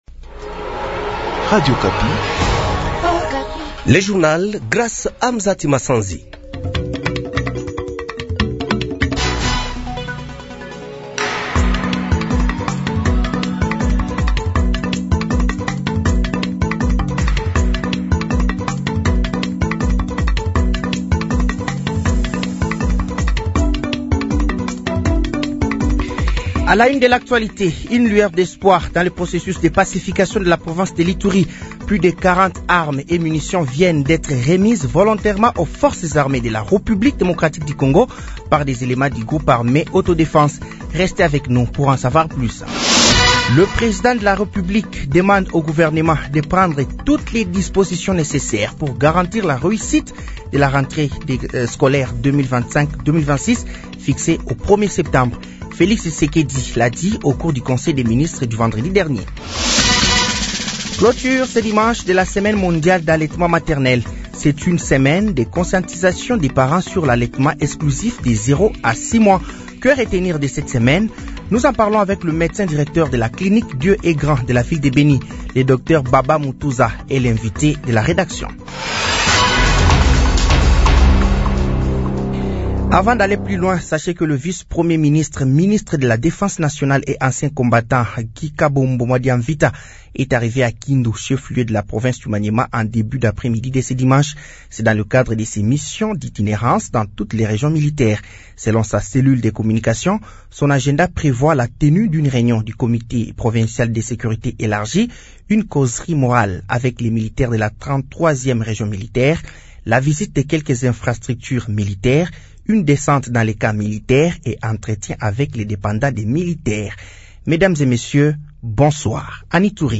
Journal français de 18h00 de ce dimanche 24 août 2025